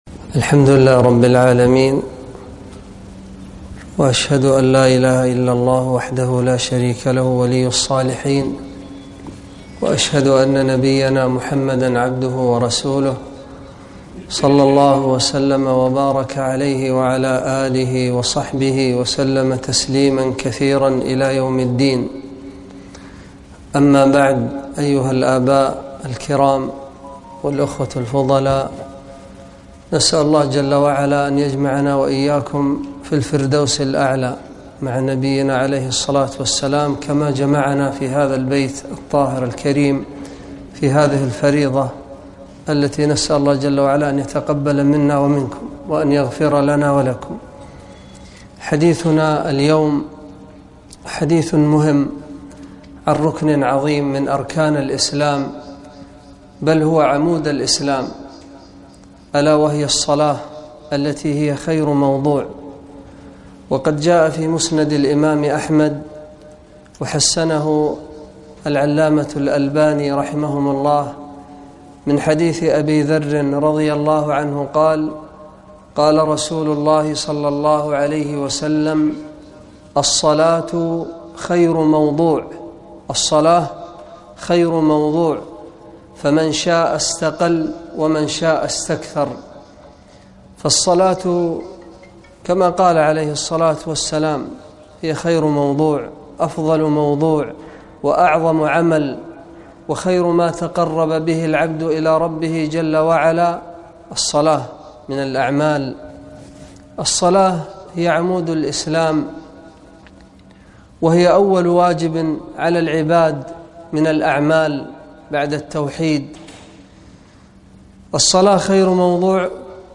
محاضرة - الصلاة خير موضوع - دروس الكويت